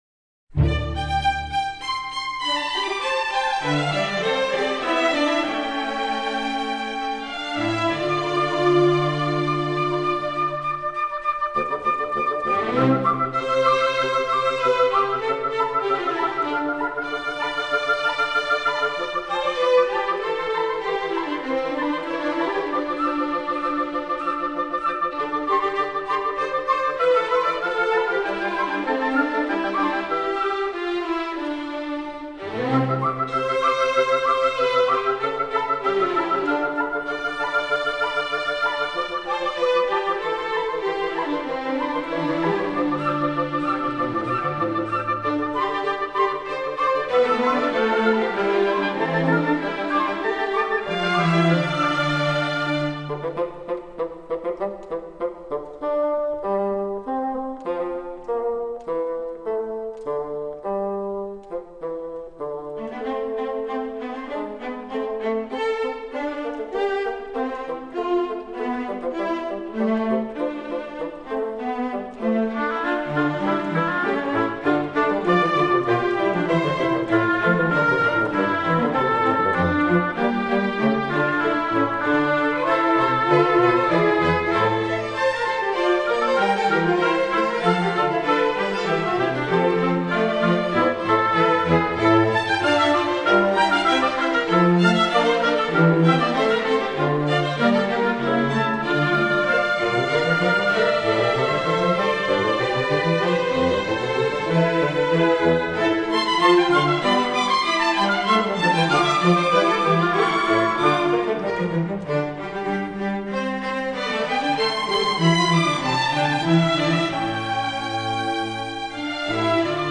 中国民族首名曲精致交响乐跨界演奏全辑
交响乐